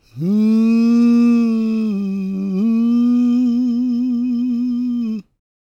GOSPMALE343.wav